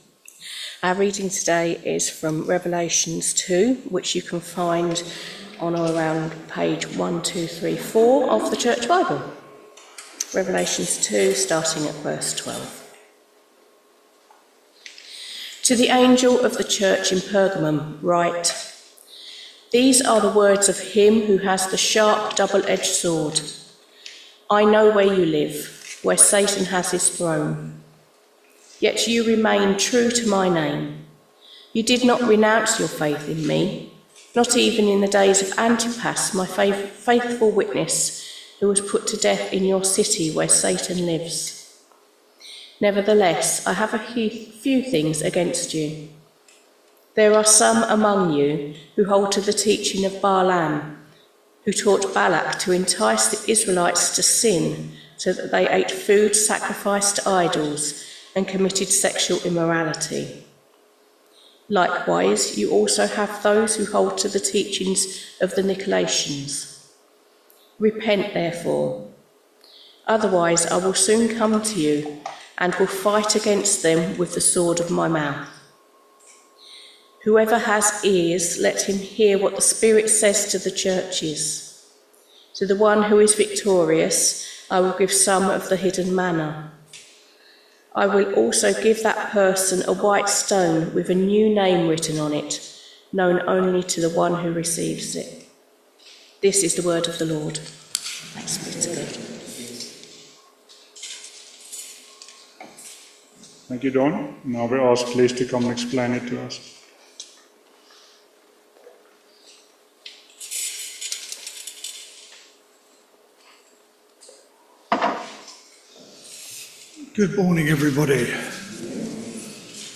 Revelation 2vv12-17 Service Type: Sunday Morning Service Topics